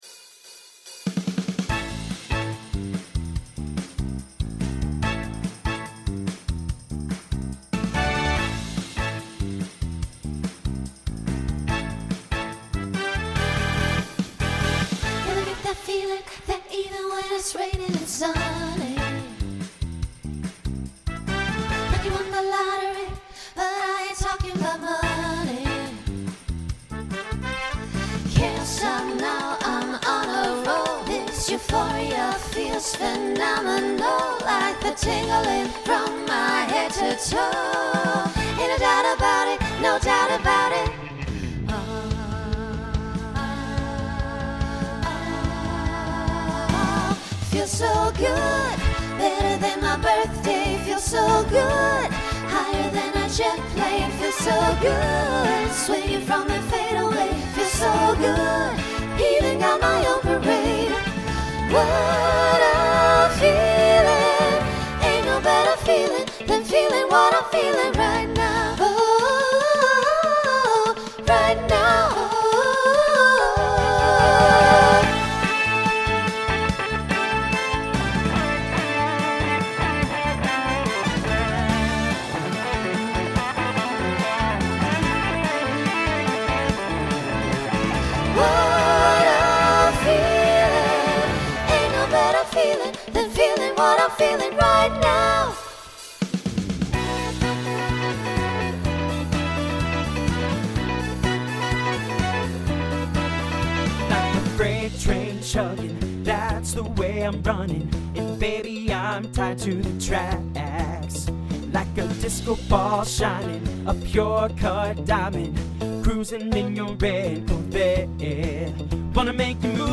SSA/TTB/SATB
Genre Pop/Dance
Transition Voicing Mixed